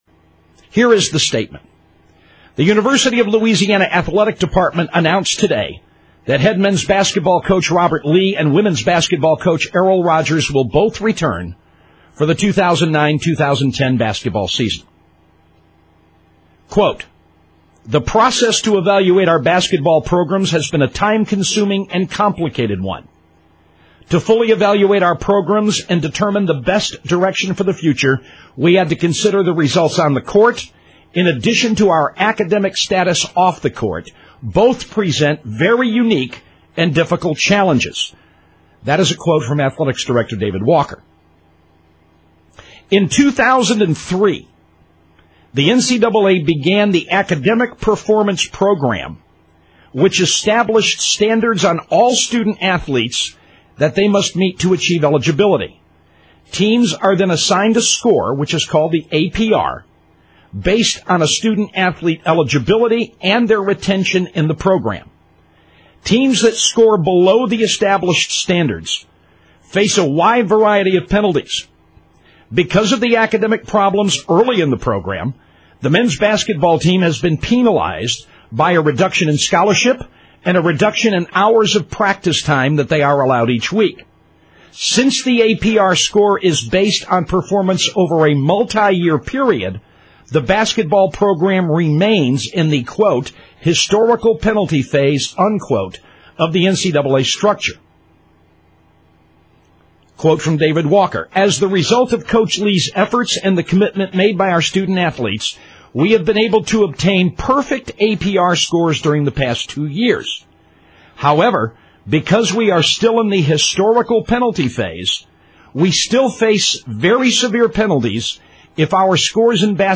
The press release is read